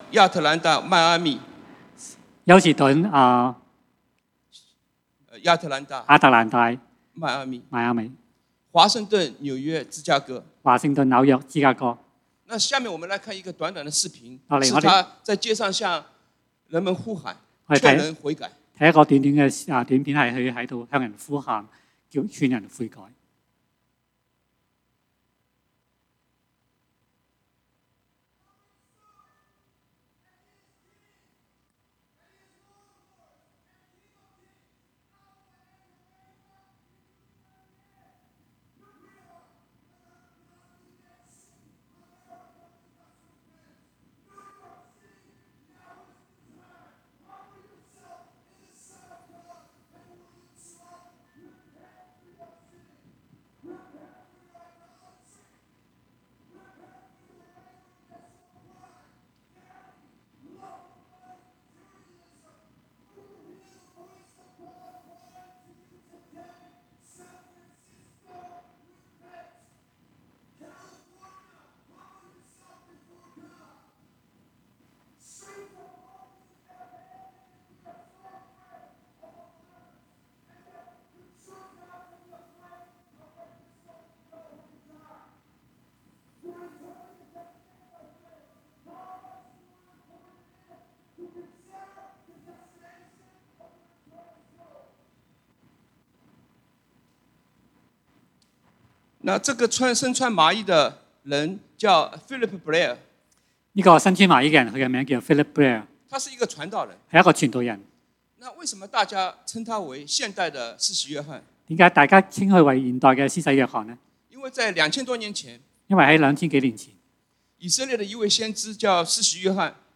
瘟疫給我們帶來的啟示 (福音主日